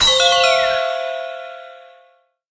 08966 glock checkpoint score
application bonus checkpoint game glock interface score sound effect free sound royalty free Sound Effects